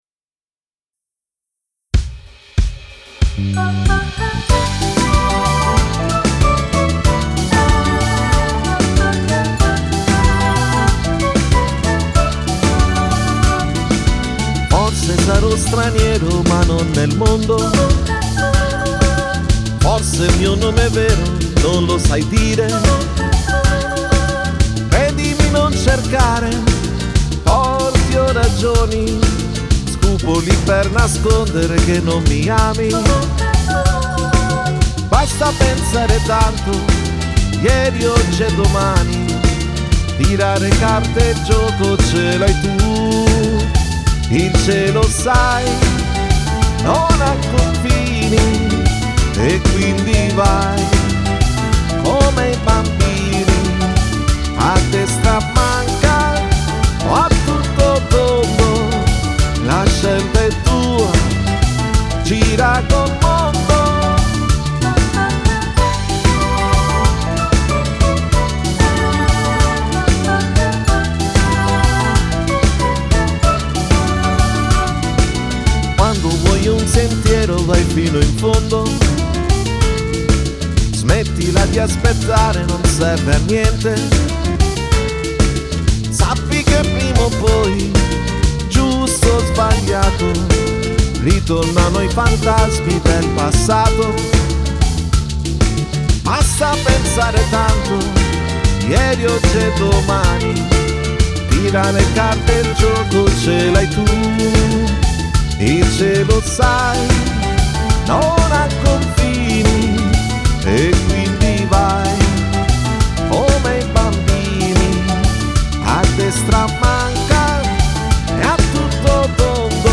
Rumba moderna